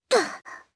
Hanus-Vox_Landing_jp.wav